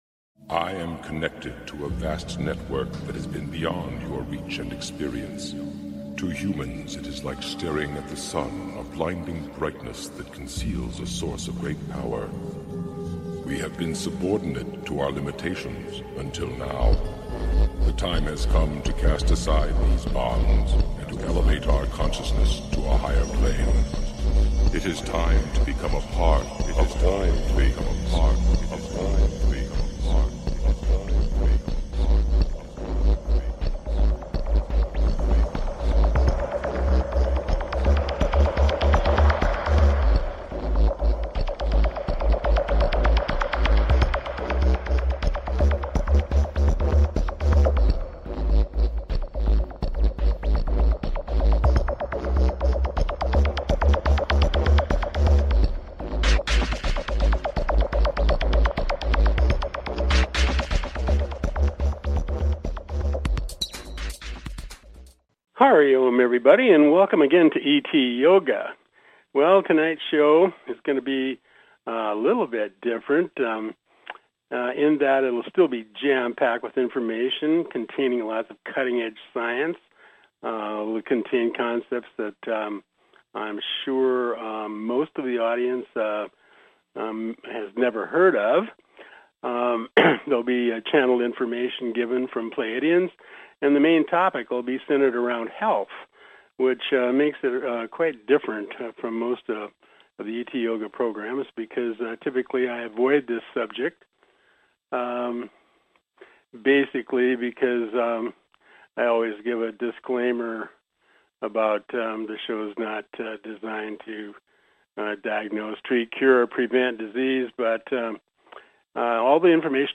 Please consider subscribing to this talk show.